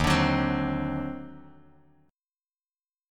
EbmM13 Chord
Listen to EbmM13 strummed